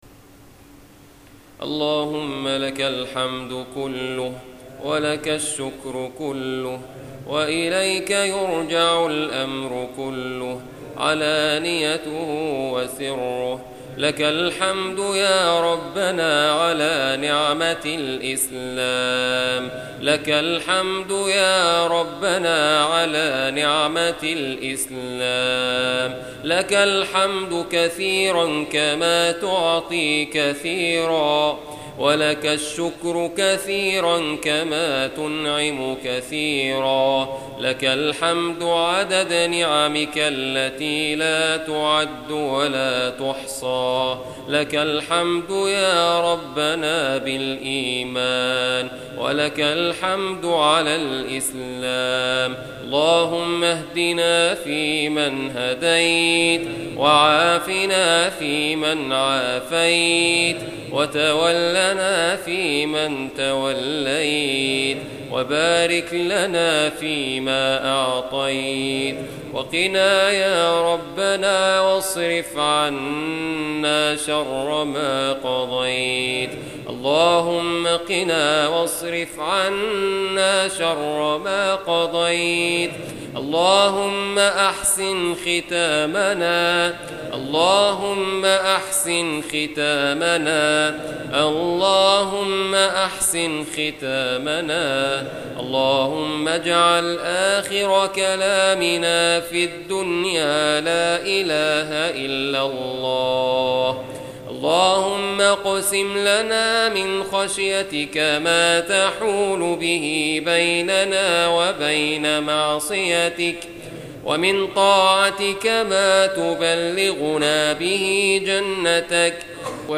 أدعية وأذكار
دعاء خاشع ومؤثر